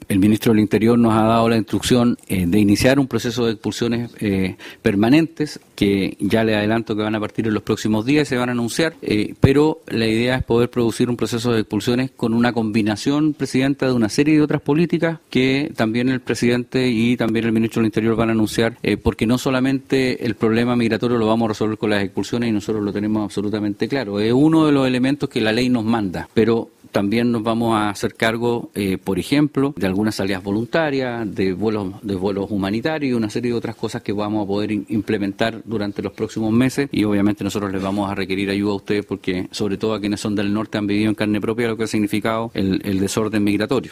La Comisión de Gobierno Interior del Senado continuó este lunes la discusión del proyecto que modifica la Ley de Migraciones para tipificar el ingreso clandestino al territorio nacional como delito.
Ante ello, el director nacional de Migraciones confirmó que actualmente existen 46 mil órdenes de expulsión firmadas y anunció que el Gobierno comenzará a ejecutarlas “en los próximos días”, mediante un proceso permanente.